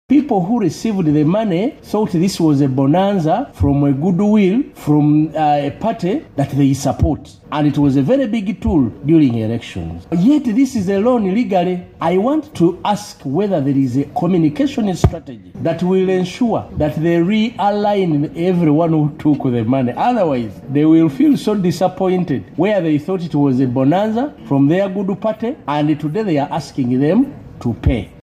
Hon. Patrick Nsamba (NUP, Kassanda County North) said many Ugandans who received PDM money construed it as a ‘goodwill bonanza’ that does not require repayment.
Hon. Patrick Nsamba Oshabe on PDM loan repayment.mp3